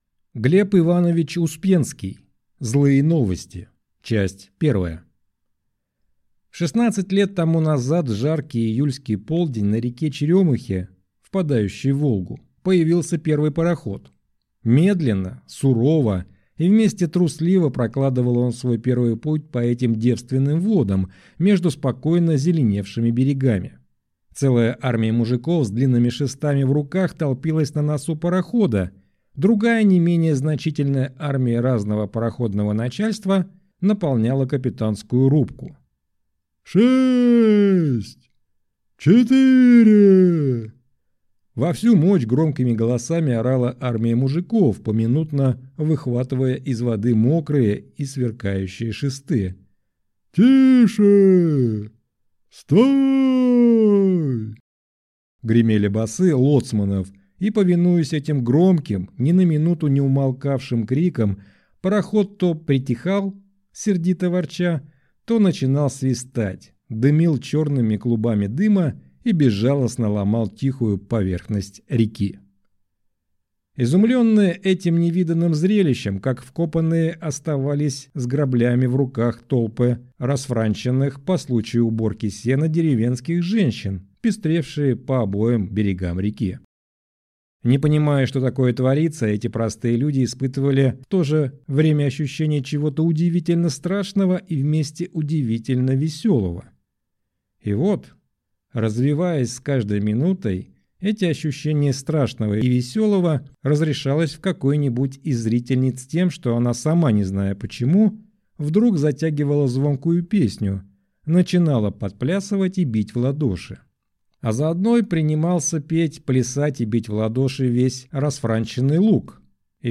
Аудиокнига Злые новости | Библиотека аудиокниг